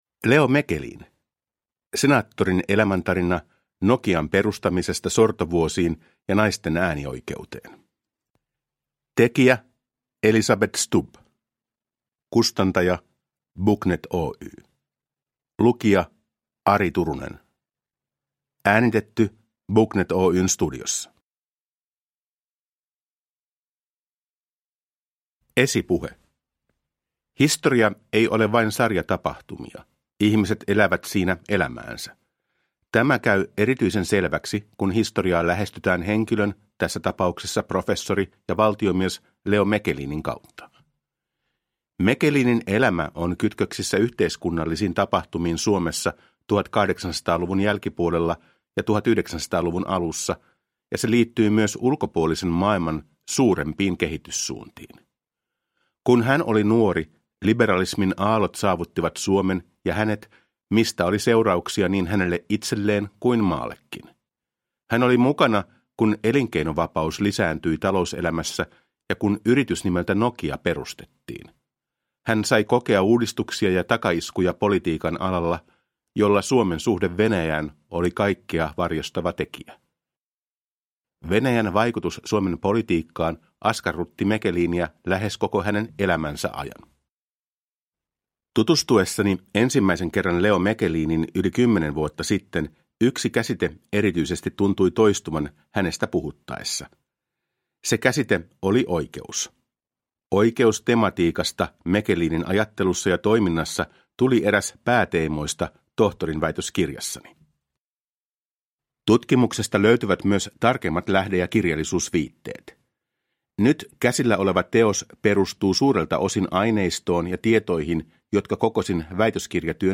Leo Mechelin - Senaattorin elämäntarina – Ljudbok